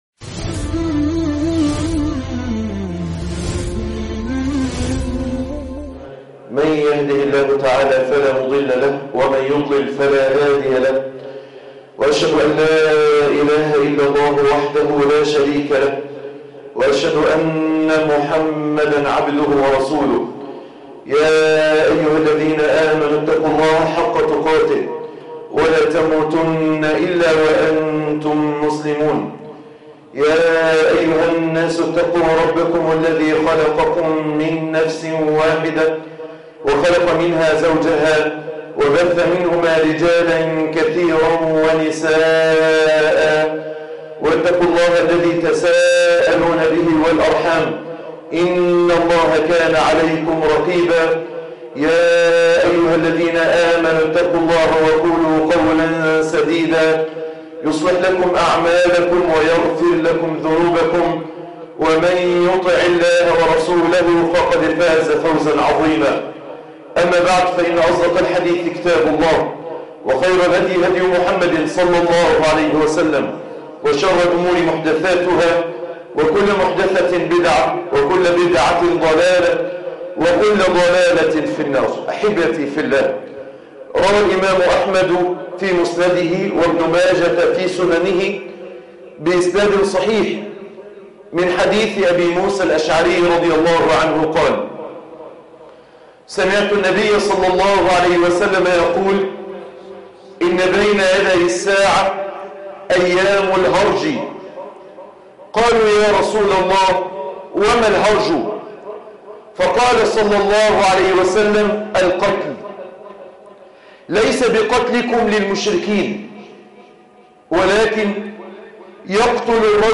نداء لعقلاء مصر - خطبة الجمعة